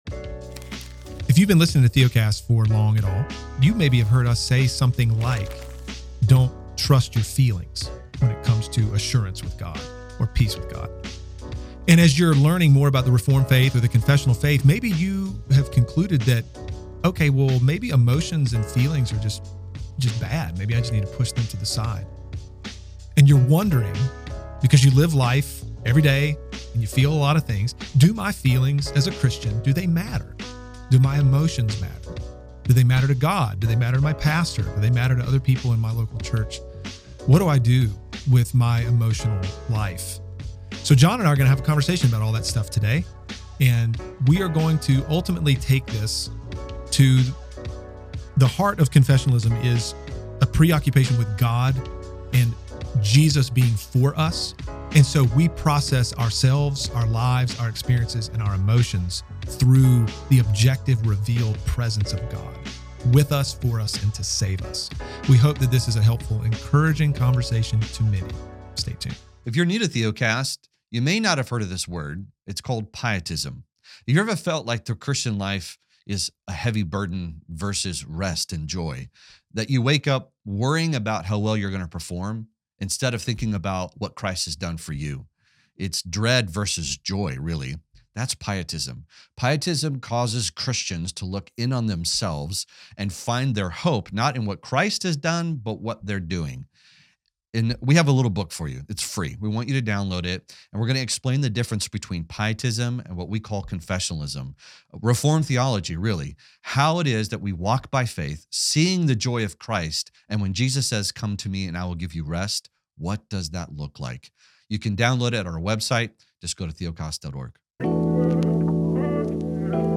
We hope this conversation will encourage and help you better understand the role of feelings in the Christian life.